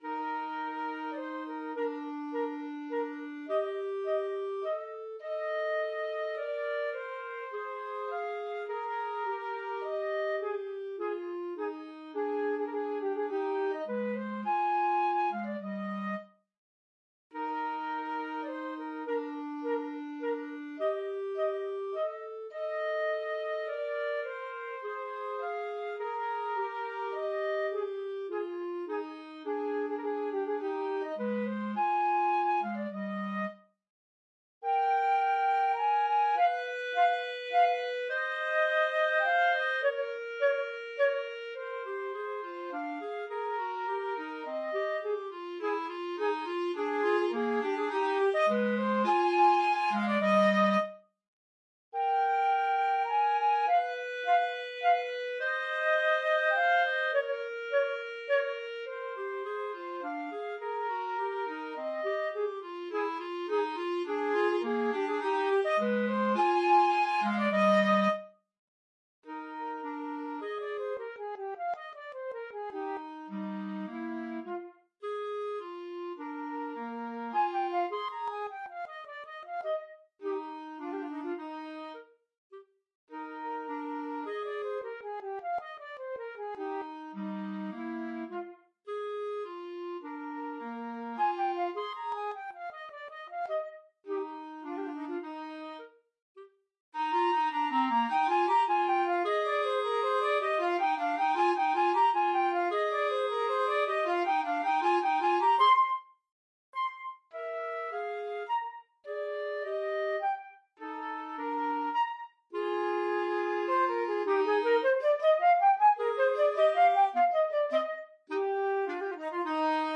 Free Sheet music for Flute-Clarinet Duet
Eb major (Sounding Pitch) F major (Clarinet in Bb) (View more Eb major Music for Flute-Clarinet Duet )
Con Grazia = c. 104
3/4 (View more 3/4 Music)
Classical (View more Classical Flute-Clarinet Duet Music)